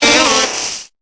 Cri de Mystherbe dans Pokémon Épée et Bouclier.